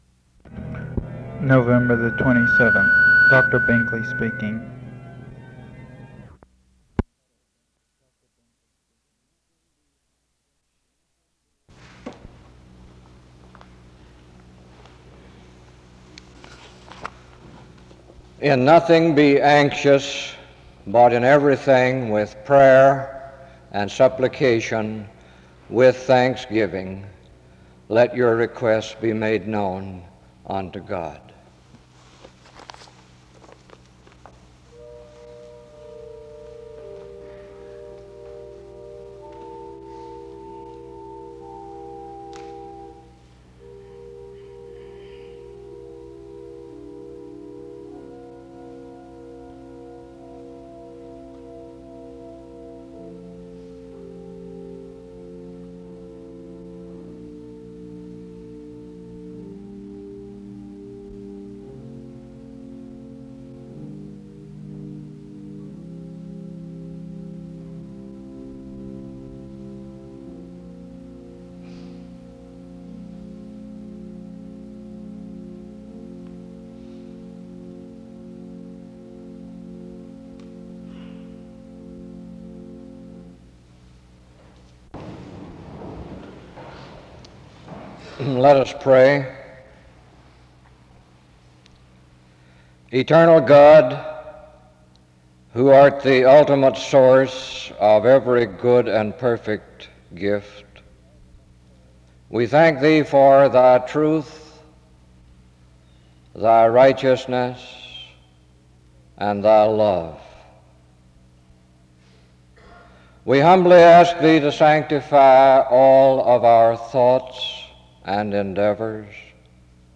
music is played from 0:18-1:24
SEBTS Chapel and Special Event Recordings SEBTS Chapel and Special Event Recordings